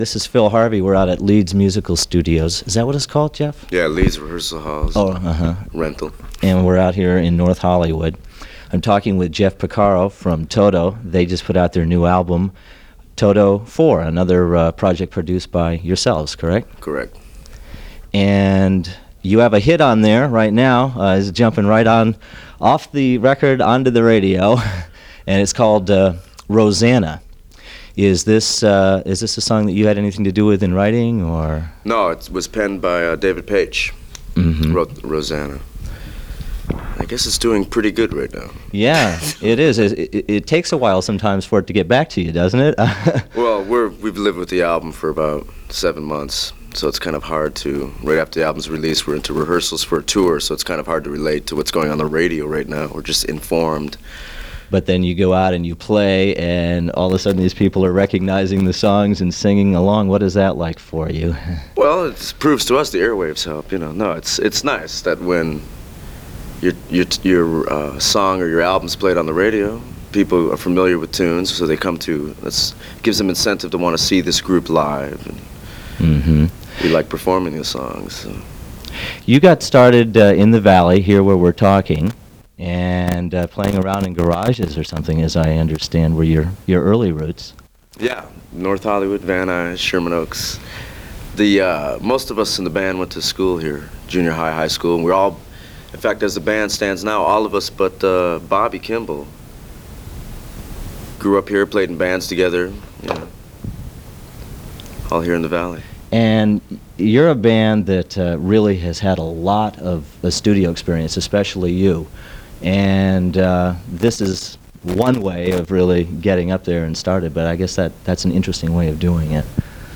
Jeff-Porcaro-Interview-1982.mp3